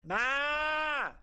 Play, download and share PİSİKO GOAT original sound button!!!!